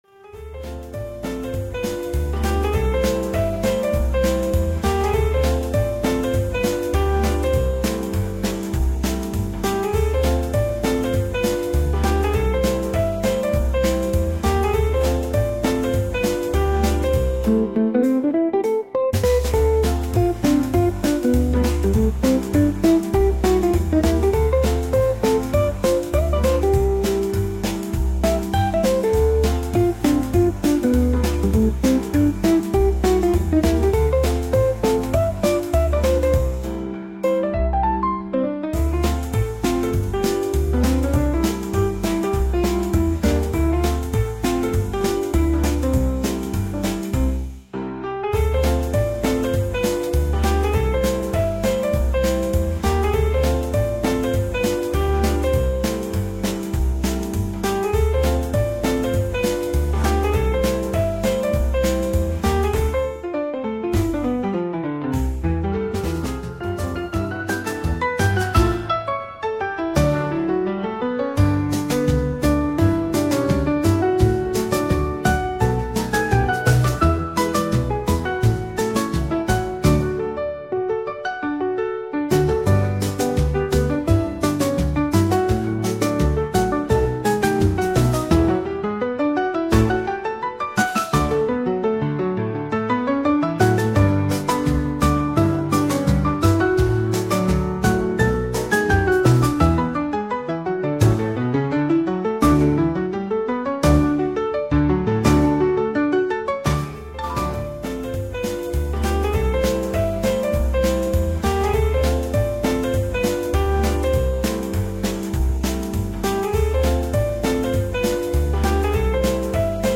こちらがレコード音質加工前の原音です
Lo-Fi jazz